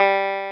CLAVI6 G3.wav